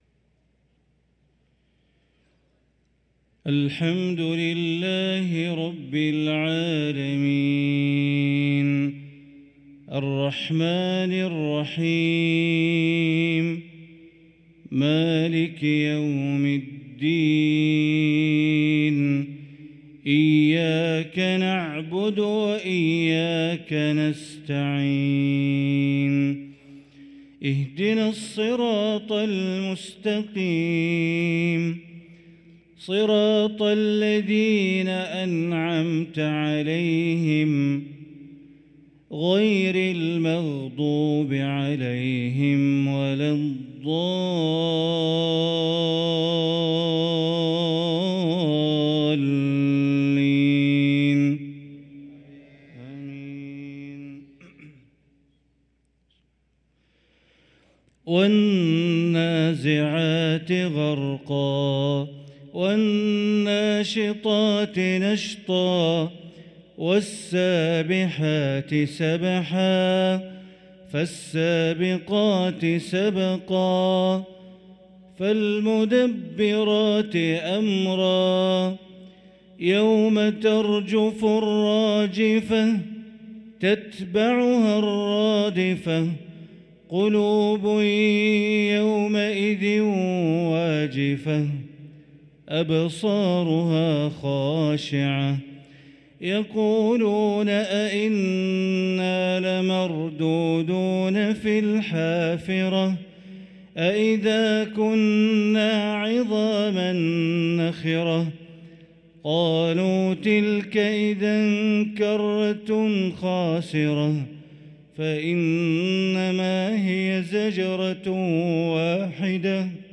صلاة العشاء للقارئ بندر بليلة 11 صفر 1445 هـ